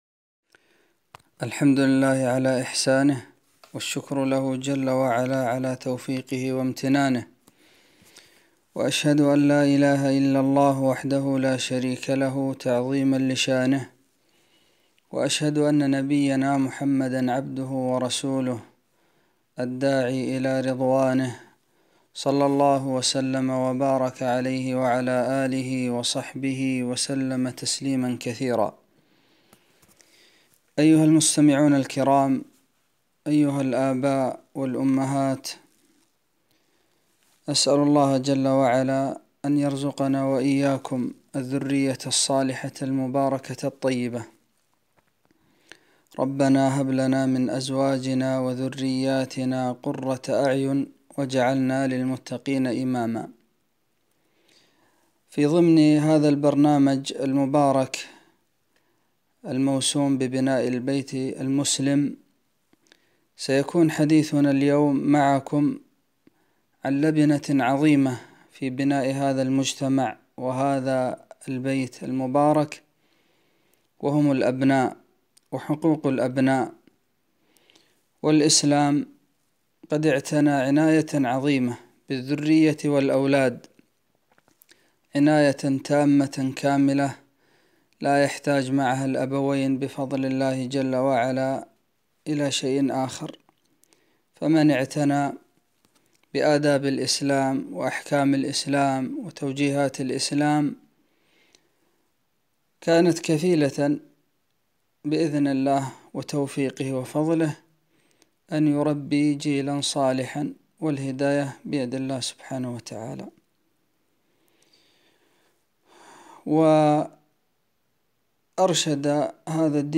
محاضرة - حقوق الأولاد